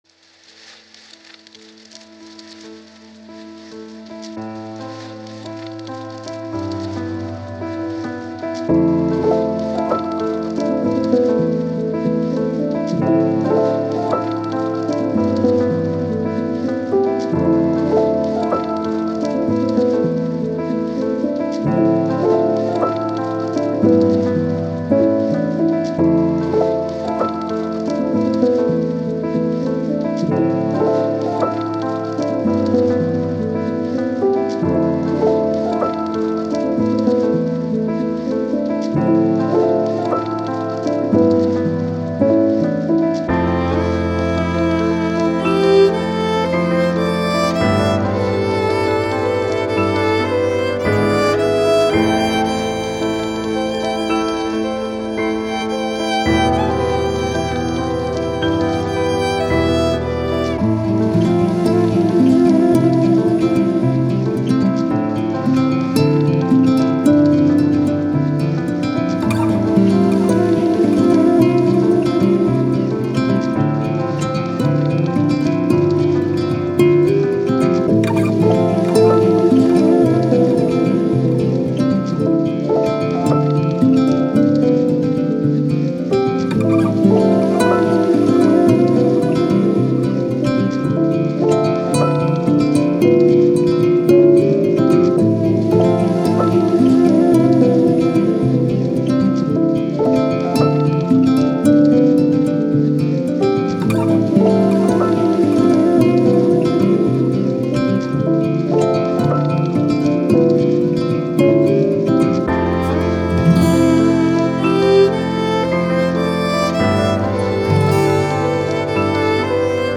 Ambient, Piano, Soundtrack, Melancholic, Thoughtful